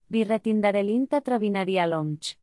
Melorin is renowned for its melodic and song-like quality, with a rhythmic cadence that flows effortlessly.
Example sentences